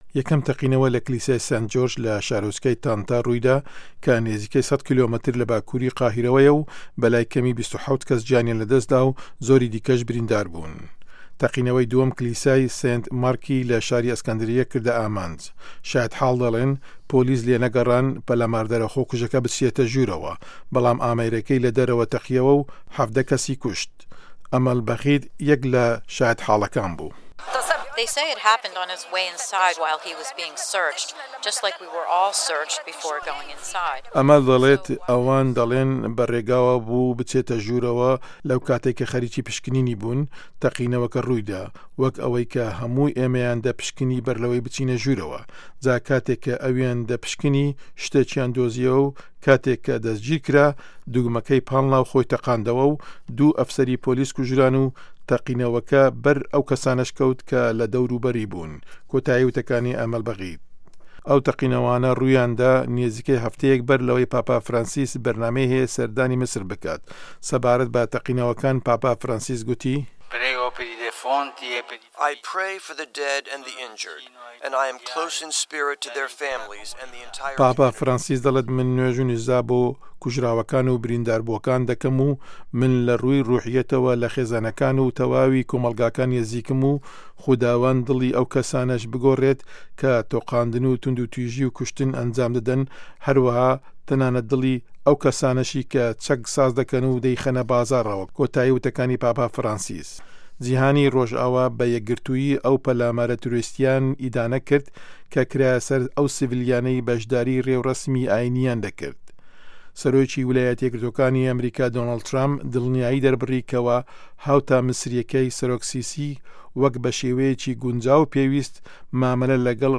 دەقی ڕاپـۆرتەکە